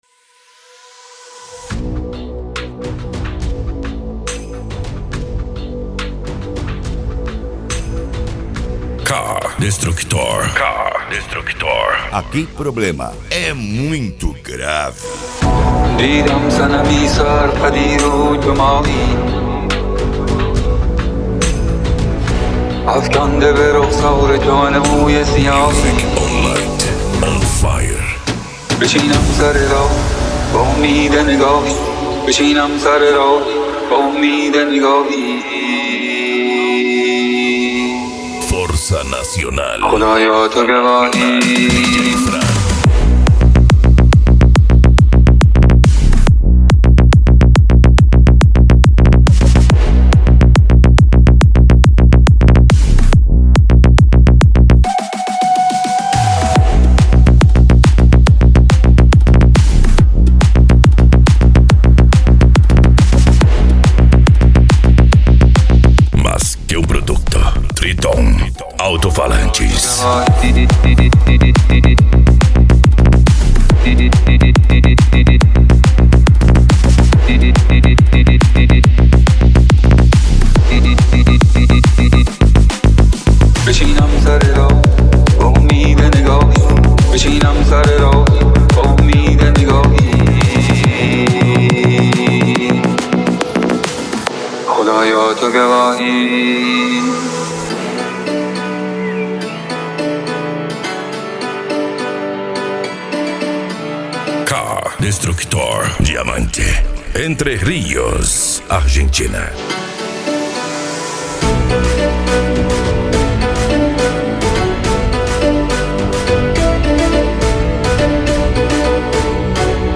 Bass
Psy Trance
Remix